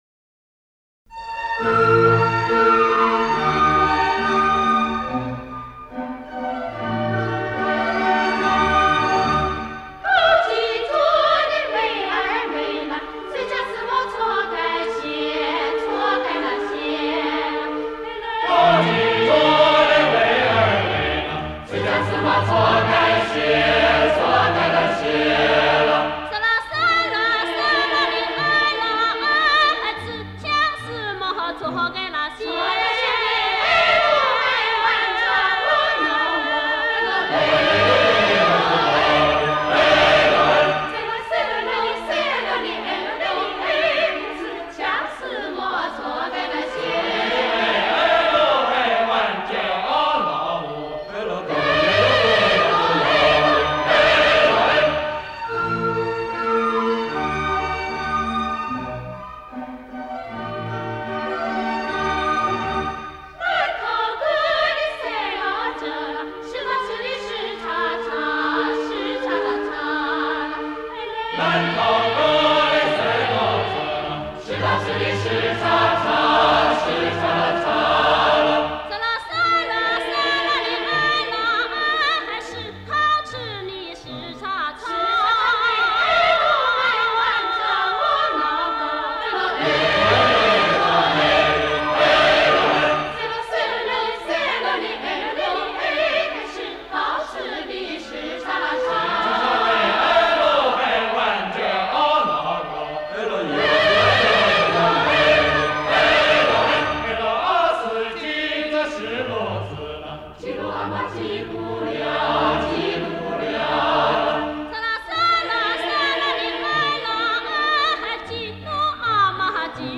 撒尼族民歌